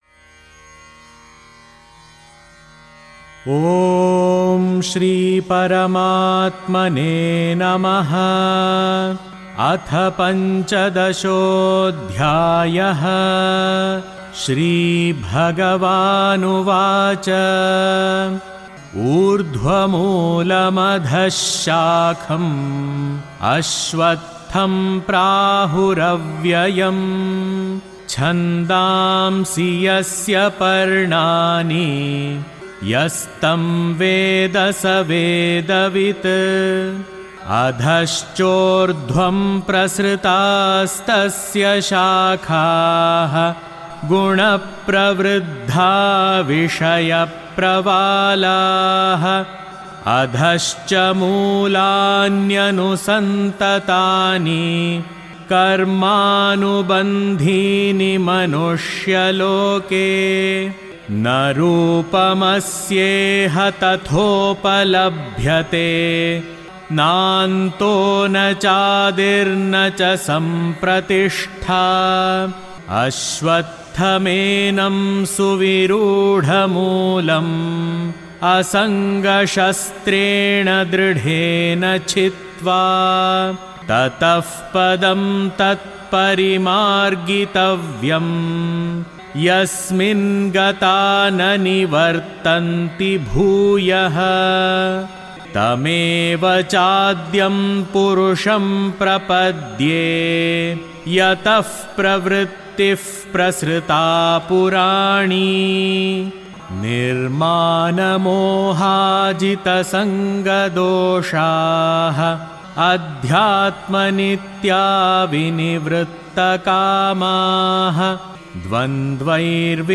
RSVP Amrit Mahotsav Chapter 15 PDF Download Chapter 15 Audio Chanting Gita Panchamrit